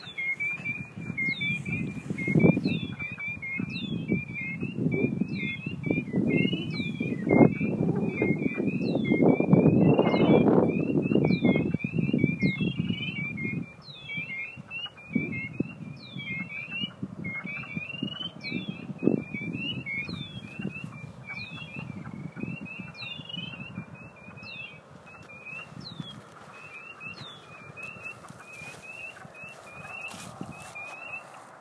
I’m not an expert, but I think I can detect at least three different species.
Peepers-and-others.m4a